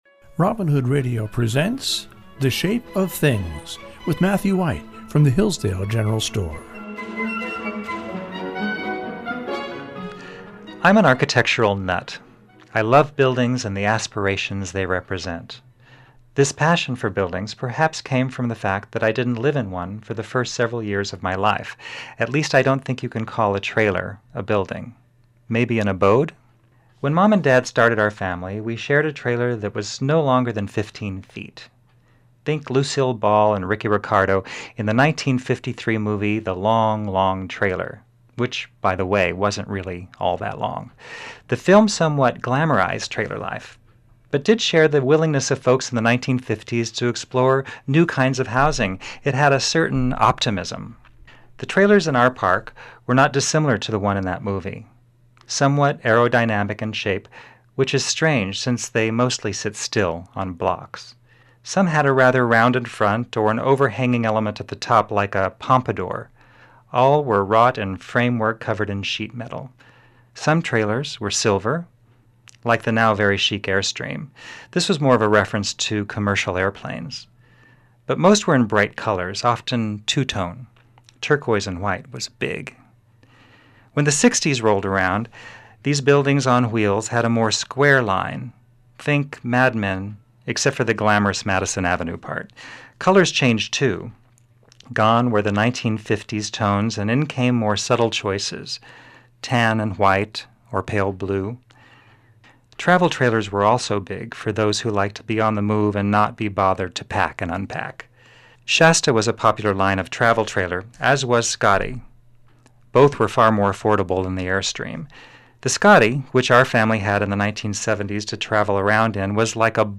reads short stories he has written based on the region.